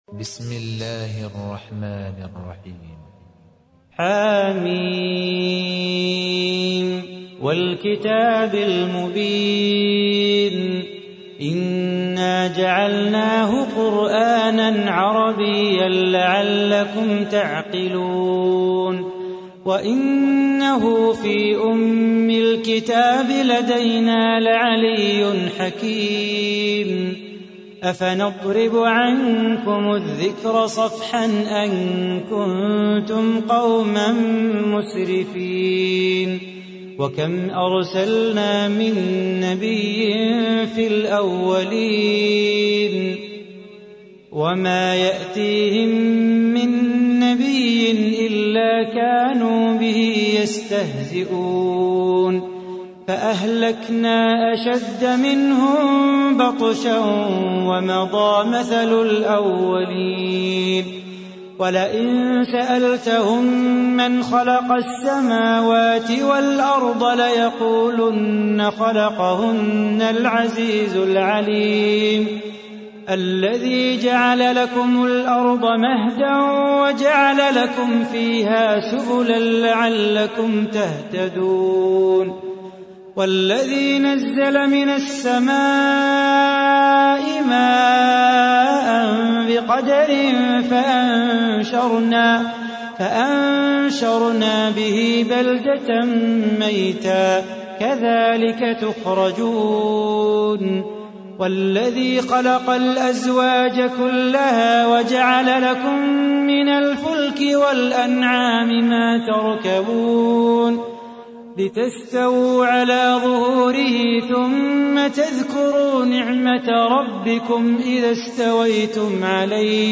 43. سورة الزخرف / القارئ